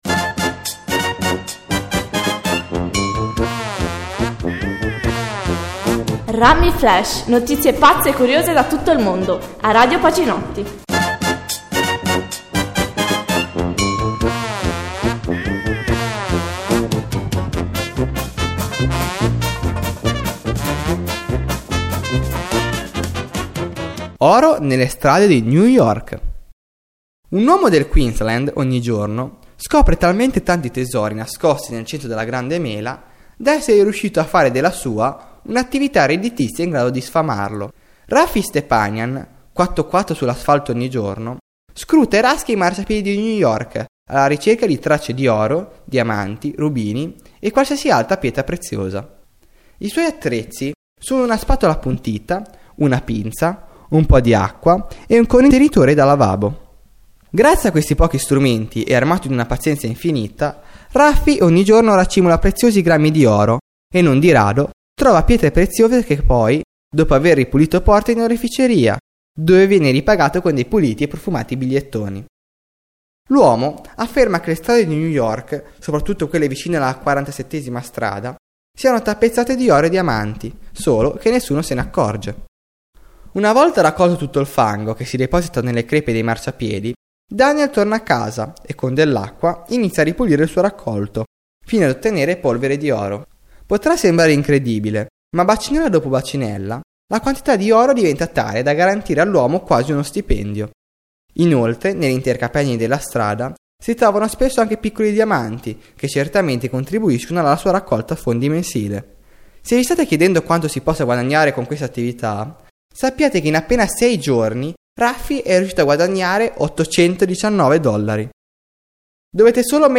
Notizie curiose e brano di musica con relazione alla notizia